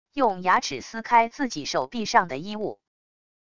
用牙齿撕开自己手臂上的衣物wav音频